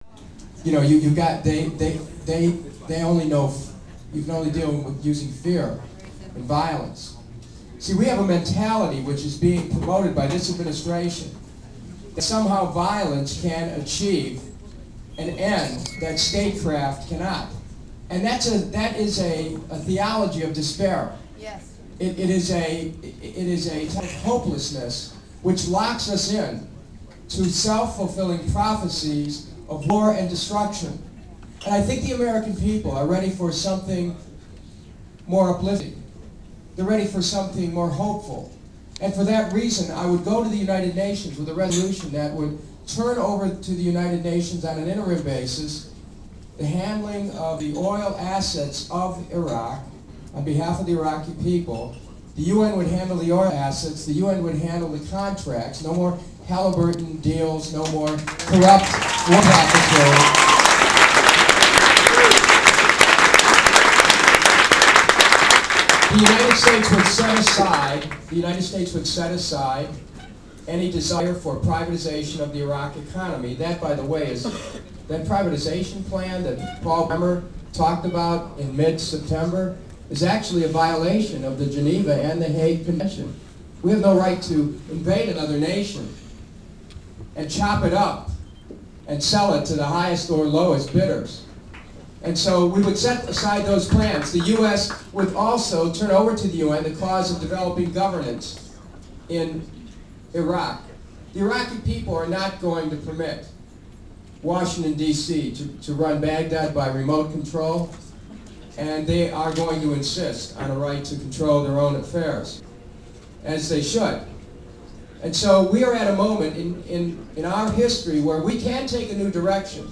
Pictures I took at the Kucinich Campaign Party in Washington, DC. plus audio files (.wav)
Kucinich speech (3.4 MB; 5mins., 25 sec.)